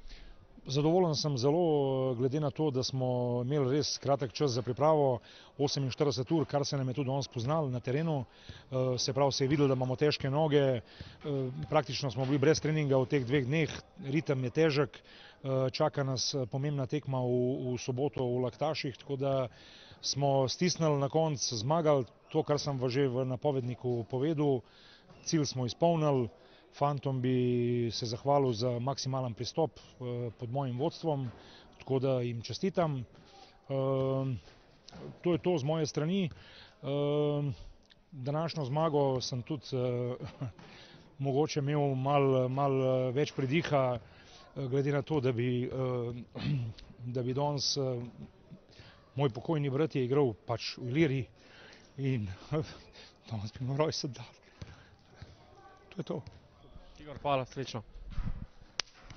Izjava po tekmi: